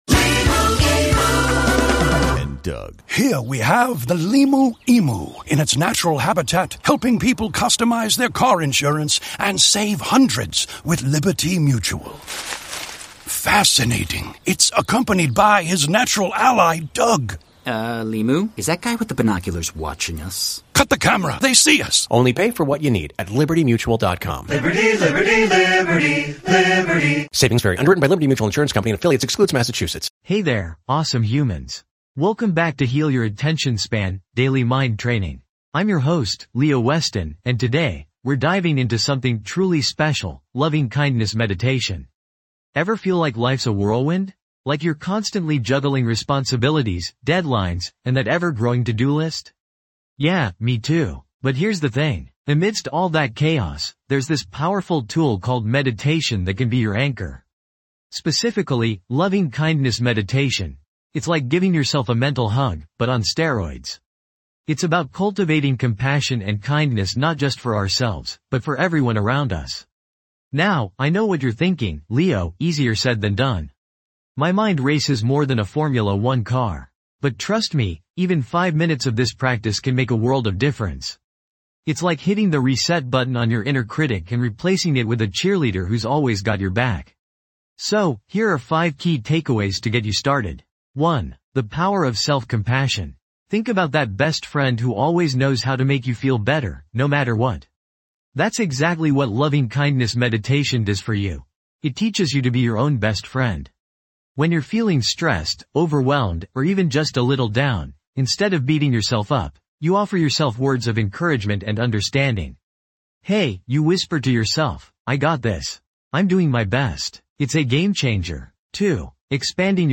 Episode Description:. Dive deep into the transformative practice of Loving Kindness Meditation designed to foster inner healing. This guided session will help you cultivate compassion, empathy, and self-love, promoting emotional wellbeing and stress reduction.
This podcast is created with the help of advanced AI to deliver thoughtful affirmations and positive messages just for you.